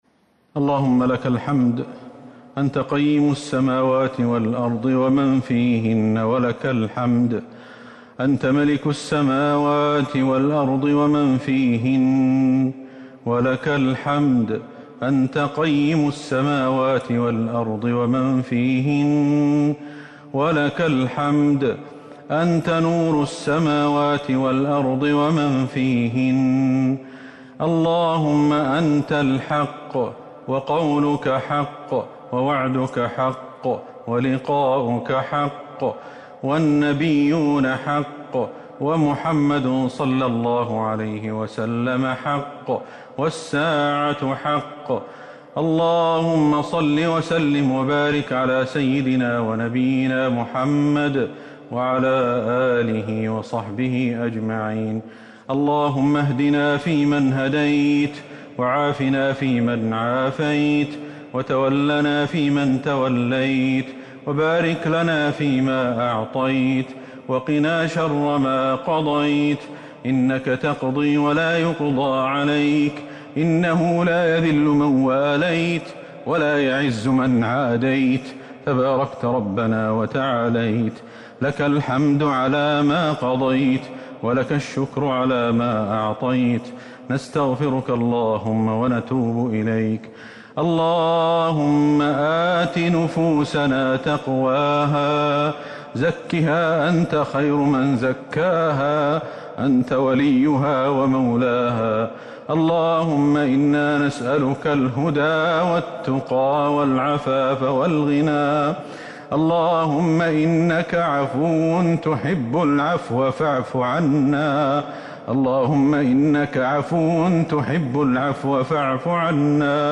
دعاء القنوت ليلة 26 رمضان 1441هـ > تراويح الحرم النبوي عام 1441 🕌 > التراويح - تلاوات الحرمين